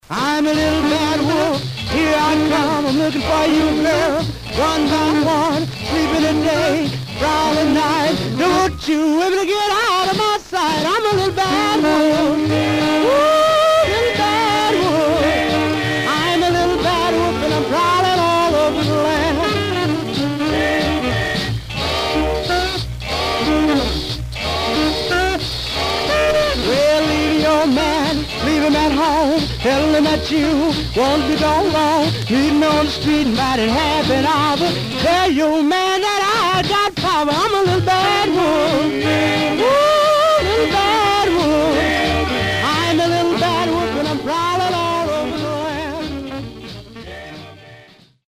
Much surface noise/wear
Mono
Male Black Group